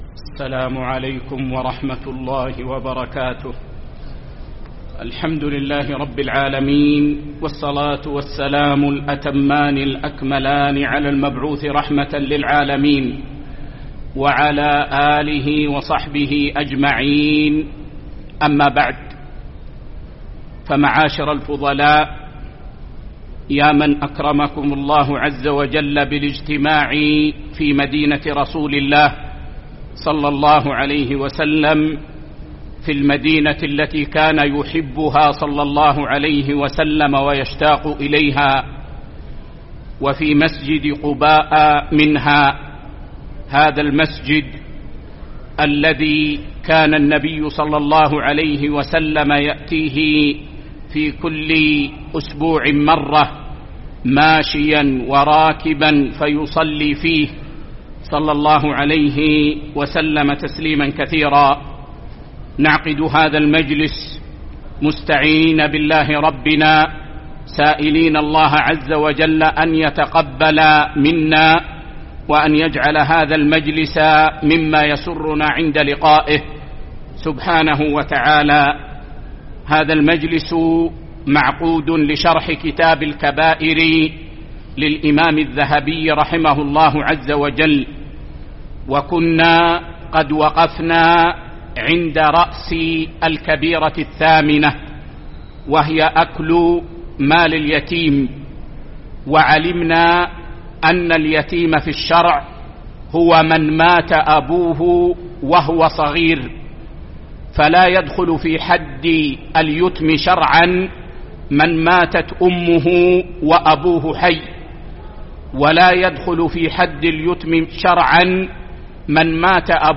الدرس 16 الكبيرة الثامنة: أكل مال اليتيم ظلمًا